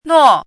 chinese-voice - 汉字语音库
nuo4.mp3